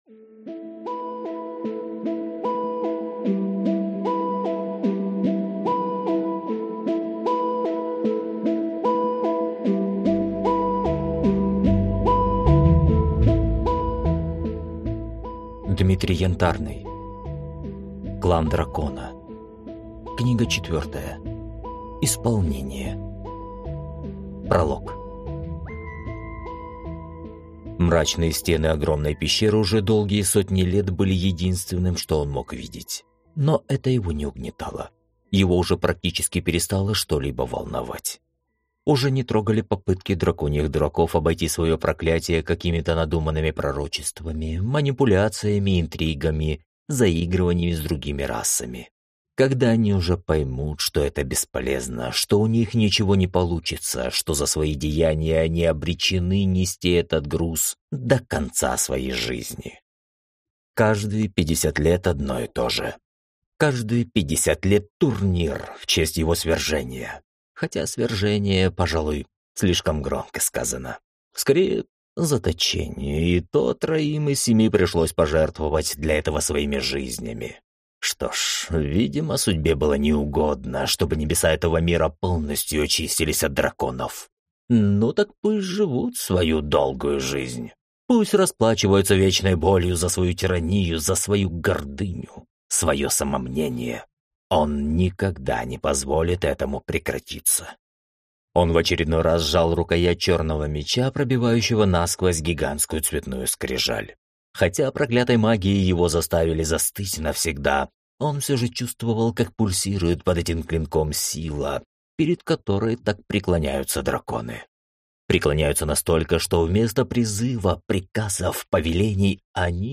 Аудиокнига Клан дракона. Книга 4. Исполнение | Библиотека аудиокниг